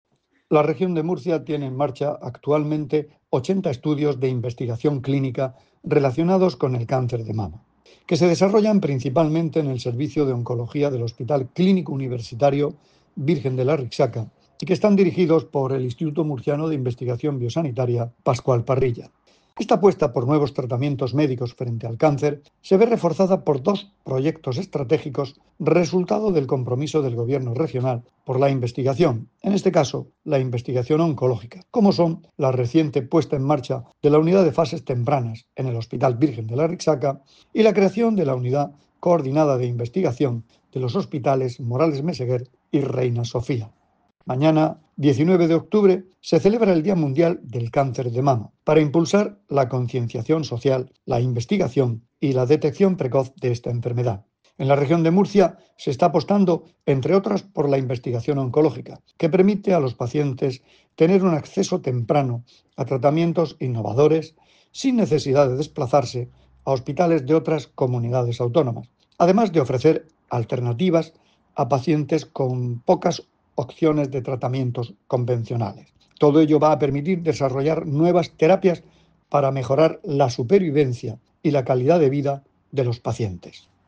Sonido/ Declaraciones del consejero de Salud, Juan José Pedreño, sobre los estudios de investigación sobre cáncer de mama que se desarrollan en la Región.